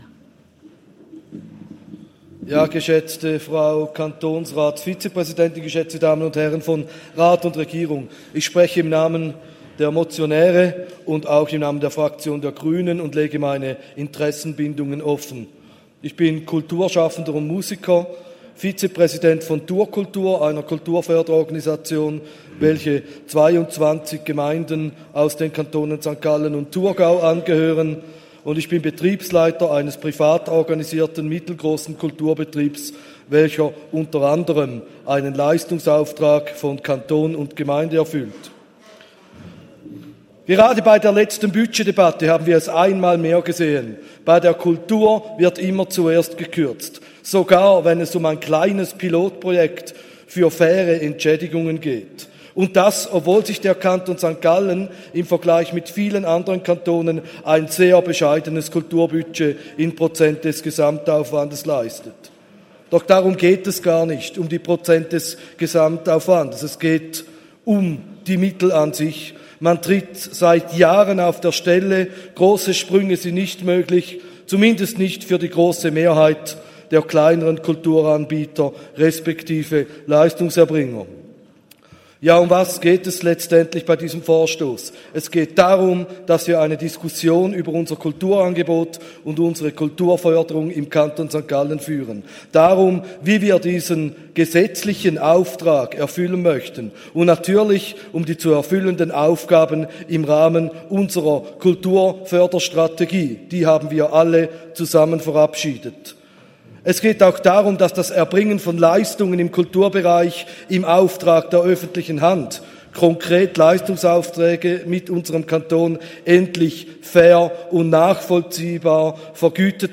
Session des Kantonsrates vom 29. April bis 2. Mai 2024, Aufräumsession
1.5.2024Wortmeldung